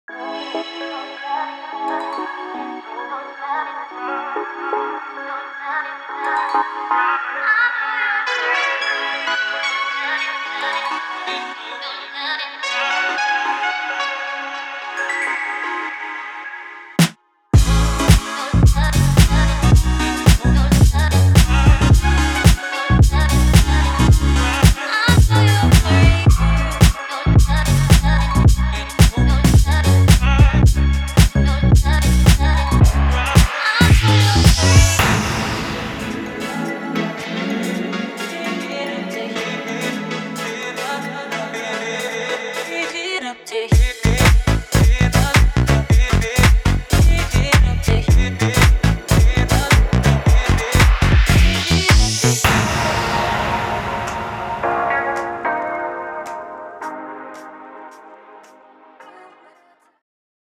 Future Bass
Deep, funky and soulful